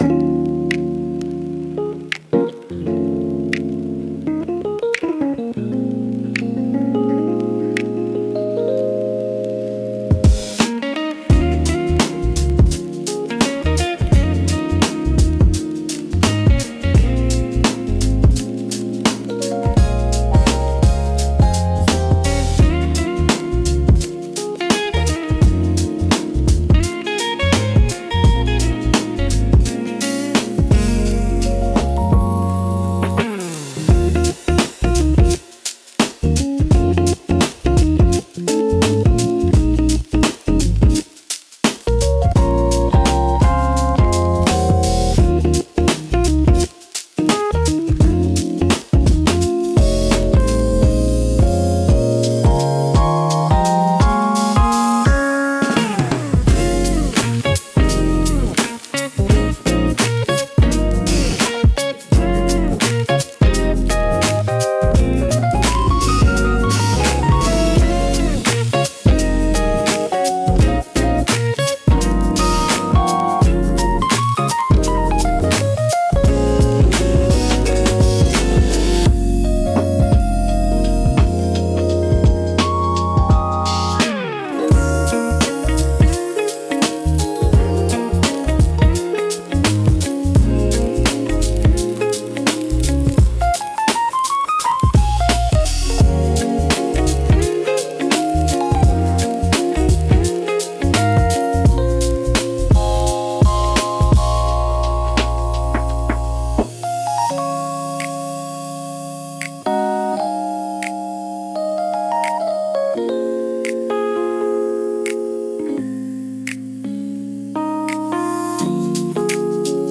Chill Lo-Fi Beat